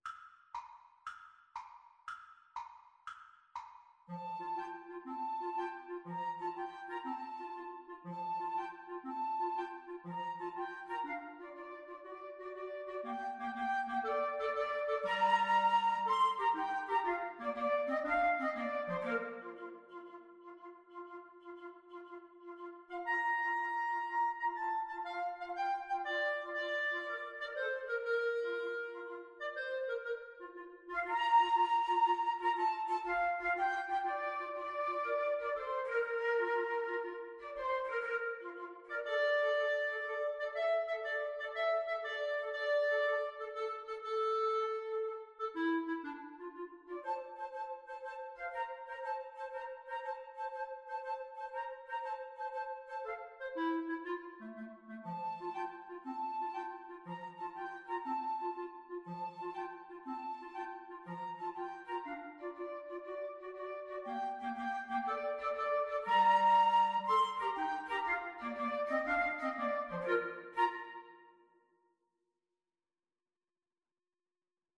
Woodwind Trio version
FluteClarinetBassoon
6/8 (View more 6/8 Music)